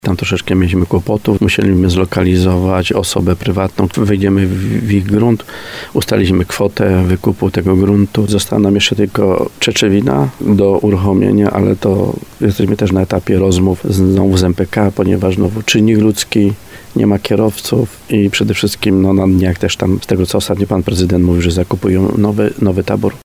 Jak mówi wójt Stanisław Kuzak, wydłużenie trasy zależało od przygotowaniem zatoki do nawracania autobusów.